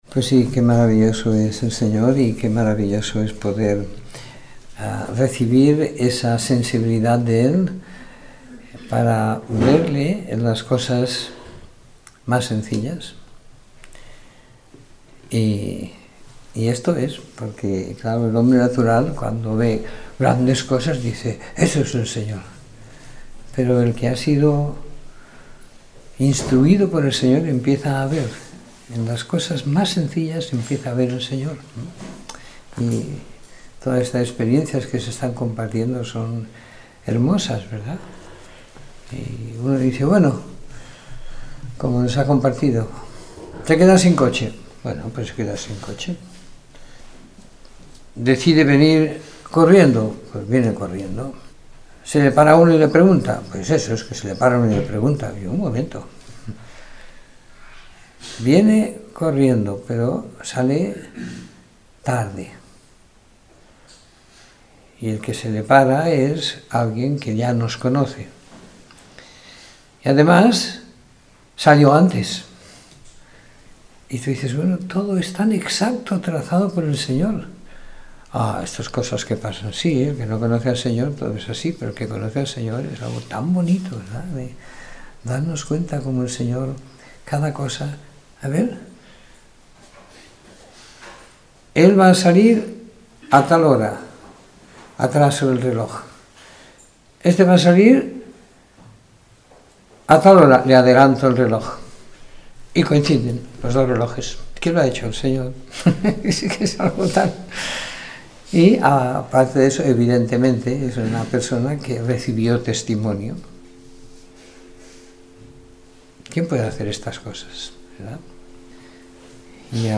Domingo por la Tarde . 05 de Febrero de 2017